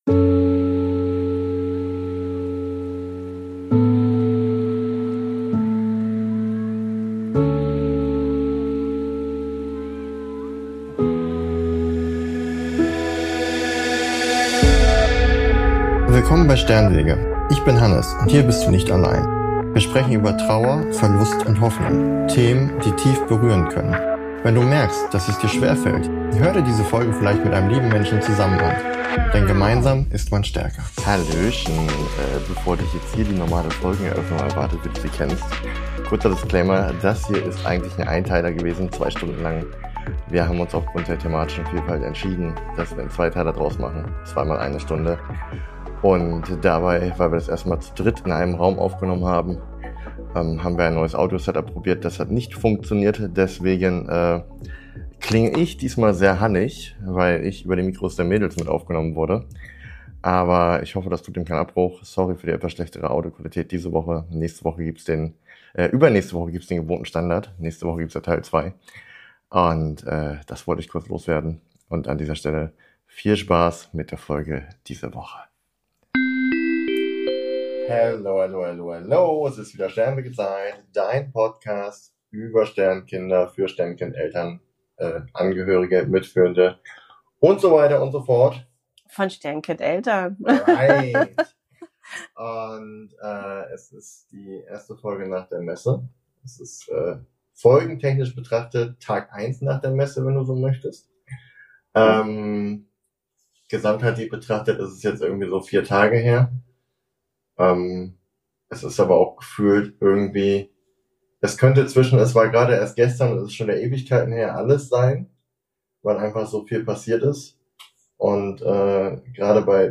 Das Gespräch führt uns von Erinnerungen und dem Gefühl von Zeit hin zu dem, was uns im Alltag immer wieder bewegt: Der Umgang mit Emotionen, das Leben mit Kindern und die Frage, wie sehr wir uns selbst darin wiederfinden.